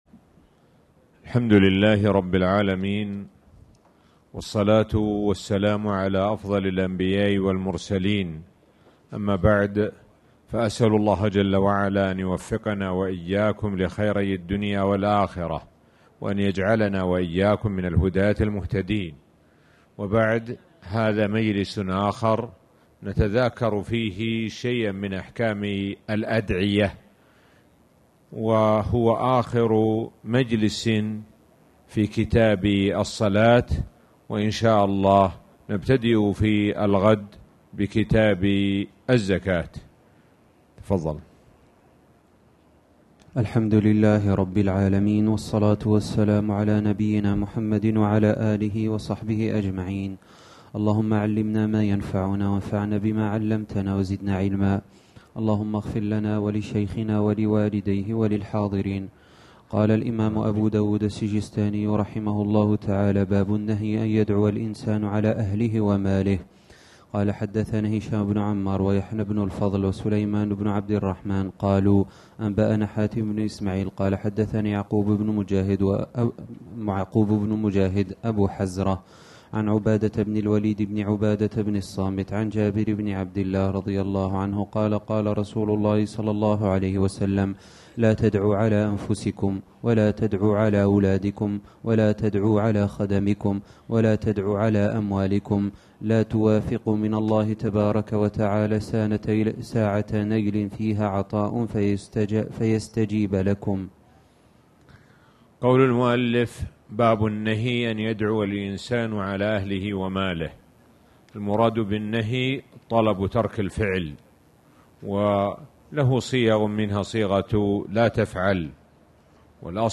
تاريخ النشر ٢ رمضان ١٤٣٨ هـ المكان: المسجد الحرام الشيخ: معالي الشيخ د. سعد بن ناصر الشثري معالي الشيخ د. سعد بن ناصر الشثري باب النهي أن أن يدعو الإنسان على أهله وماله